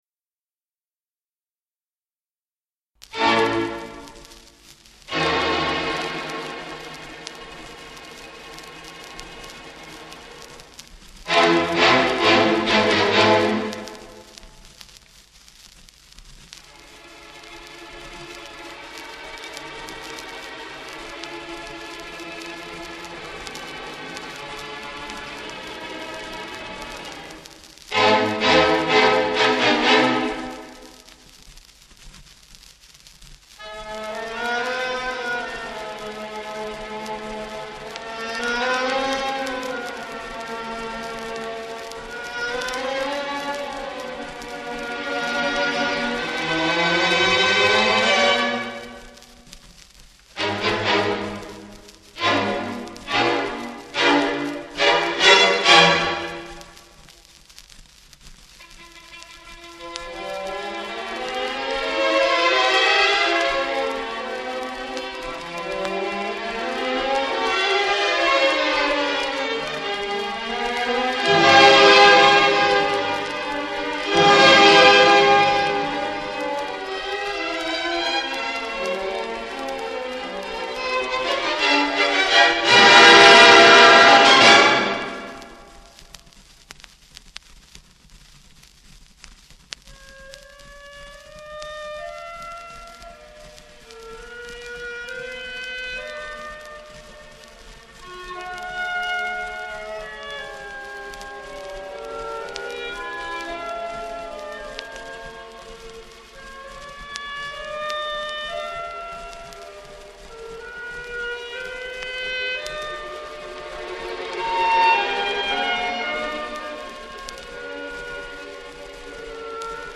The Original Orchestrations of Favorite Arias.